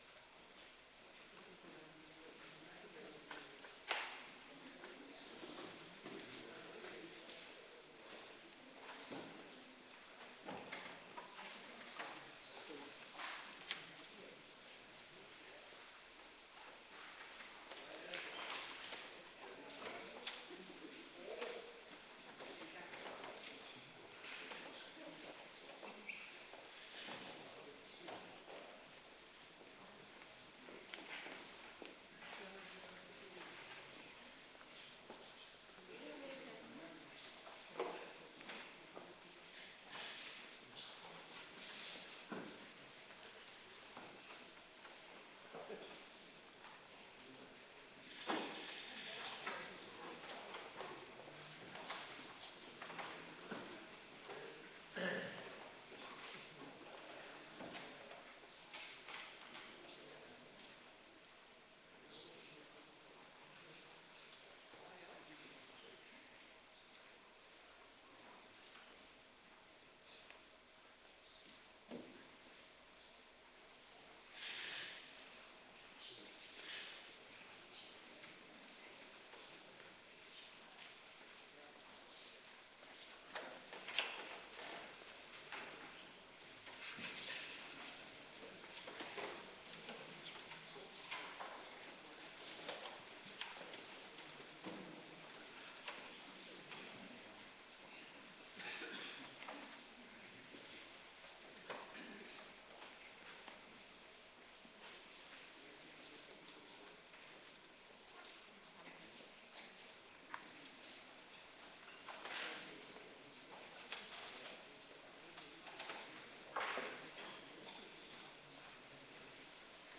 Kerkdiensten
Klik hier om de dienst op YouTube te bekijken GOEDE VRIJDAG Commissie Stille week Organist